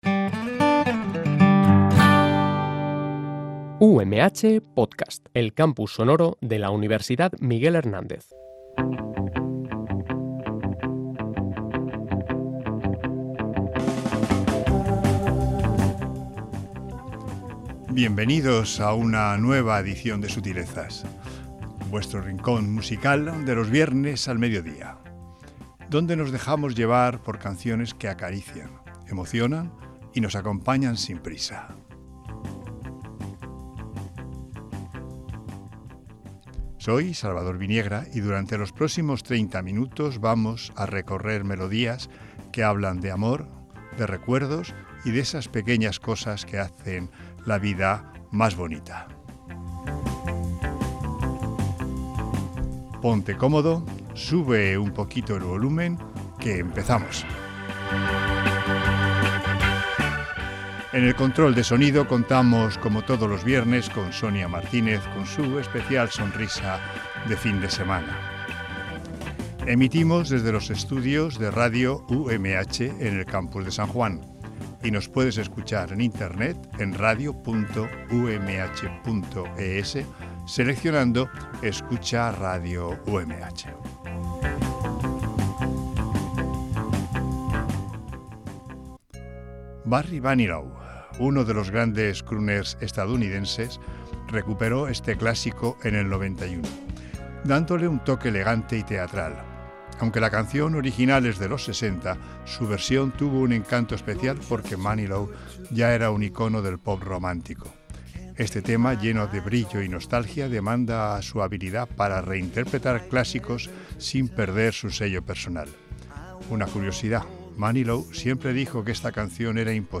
Este tema, lleno de brillo y nostalgia, demuestra su habilidad para reinterpretar clásicos sin perder su sello personal.
Este tema, publicado en 2020, es una declaración de amor sencilla y directa, con una producción muy limpia que deja espacio a su voz cálida.
Le dieron un aire más contemporáneo, manteniendo la fuerza melódica del original.